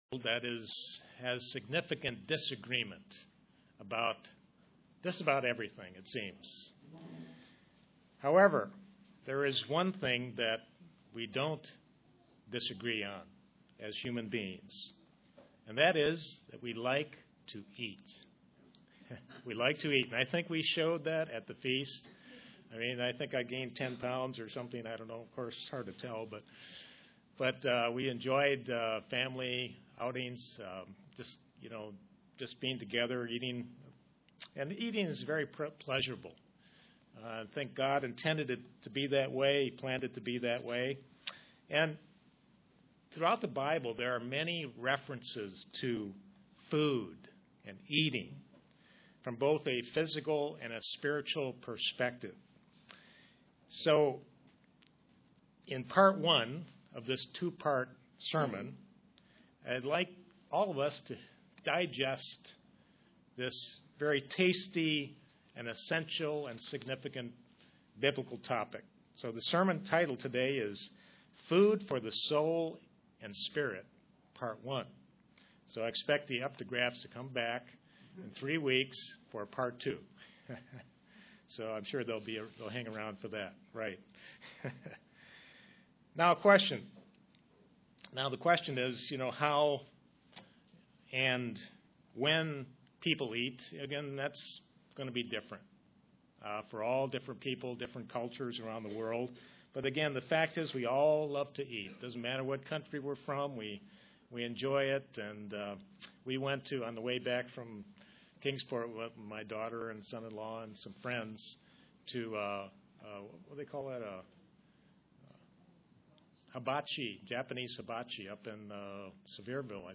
Print How knowing our Bible and prayer life helps a Christian UCG Sermon Studying the bible?